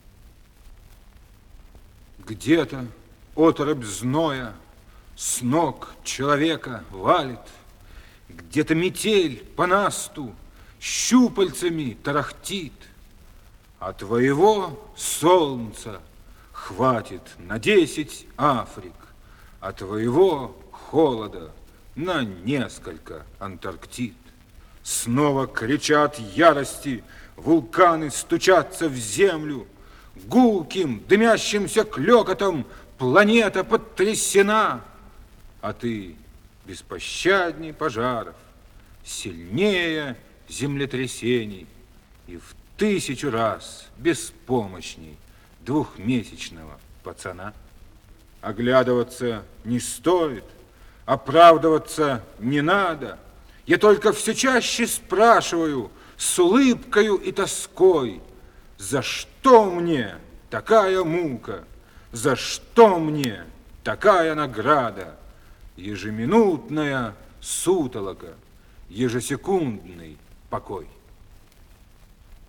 Rozhdestvenskiy-Alenke-chitaet-avtor-stih-club-ru.mp3